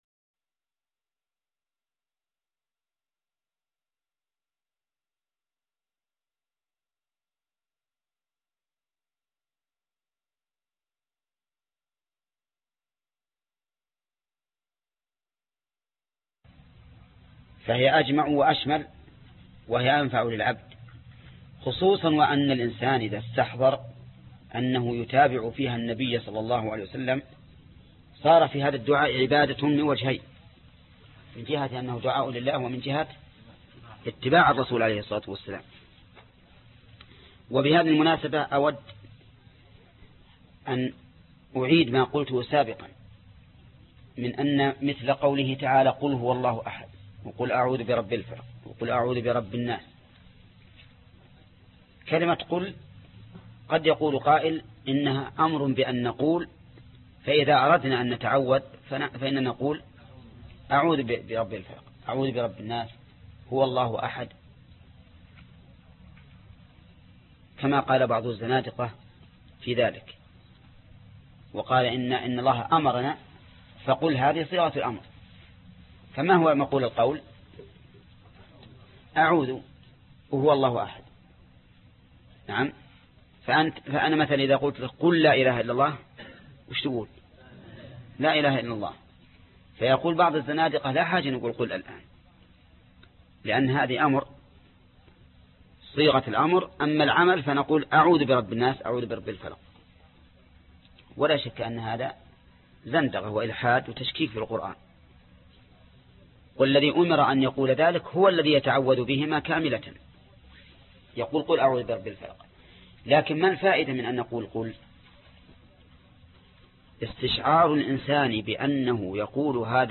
الدرس 12 باب الاستسقاء بذوي الصلاح6 (شرح كتاب الاستسقاء من المنتقى) - فضيلة الشيخ محمد بن صالح العثيمين رحمه الله